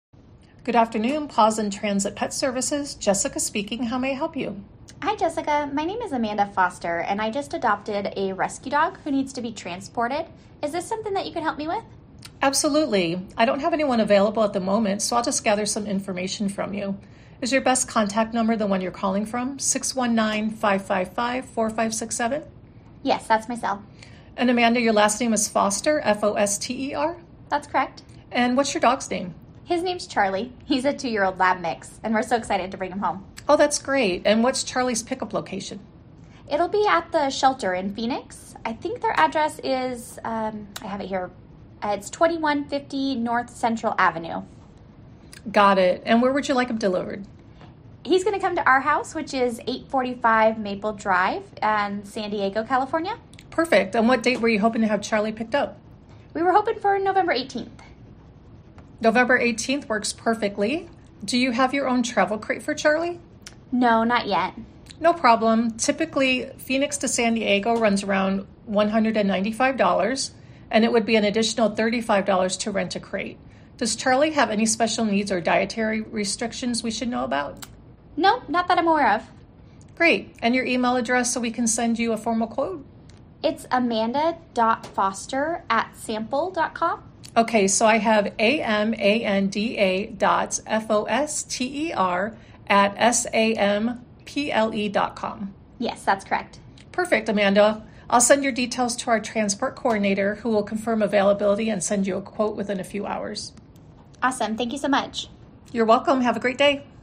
small-business-services-answering-service-sample-call.mp3